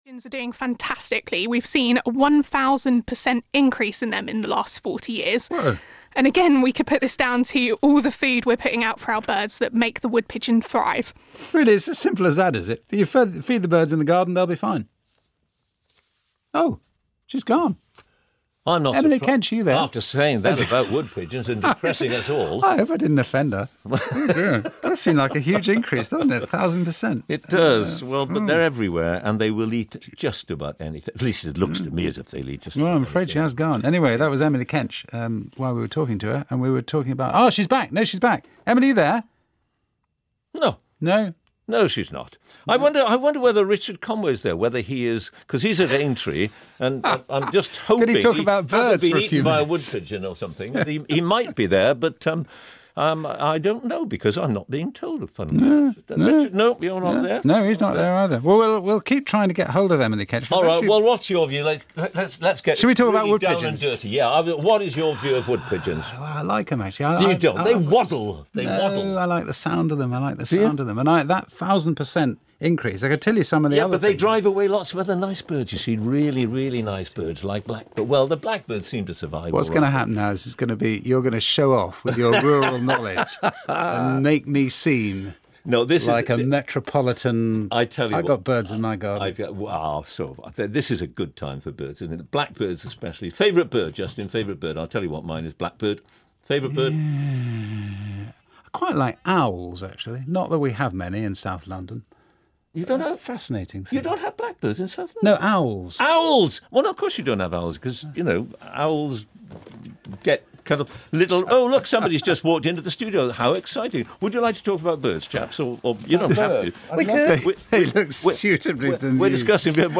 Light relief on the Radio 4 Today programme from 6th April 2019 with John Humphrys and Justin Webb when the line went down and filling ensued...